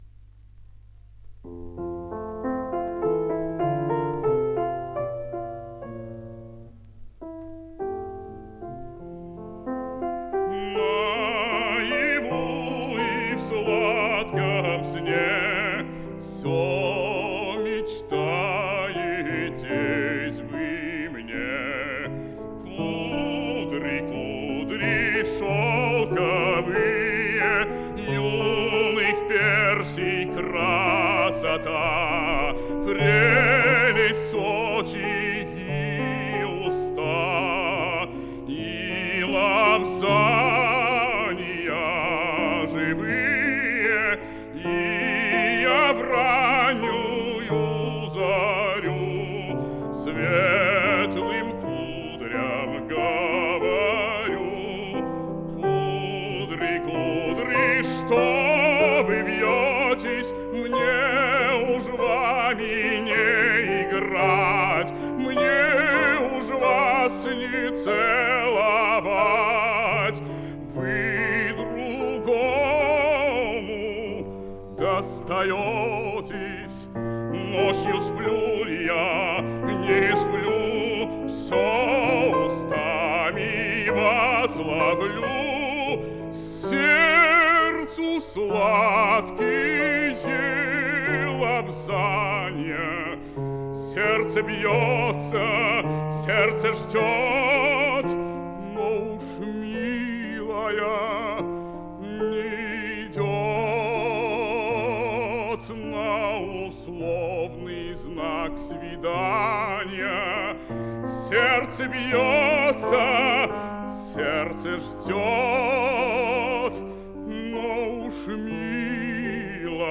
фп.) - Кудри (А.Даргомыжский - А.Дельвиг) (1952)